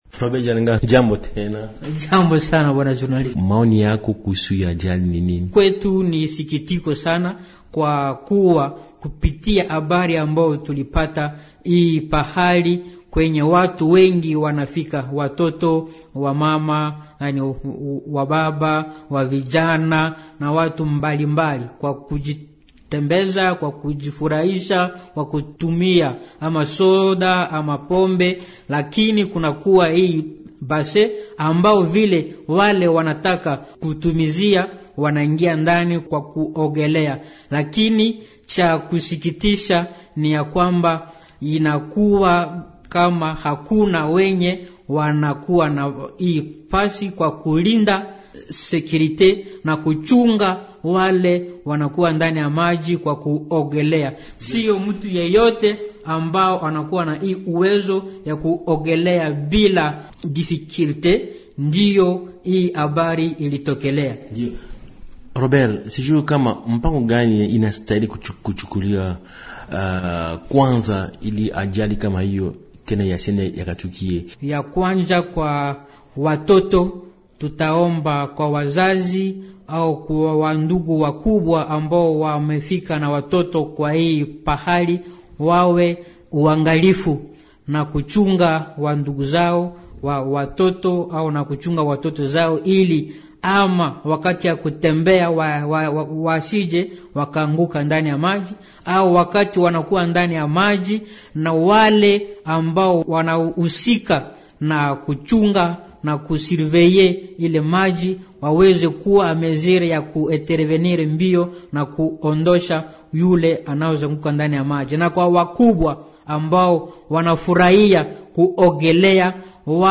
L'invité swahili, Émissions / Institut Supérieur de Management, ISM, étudiants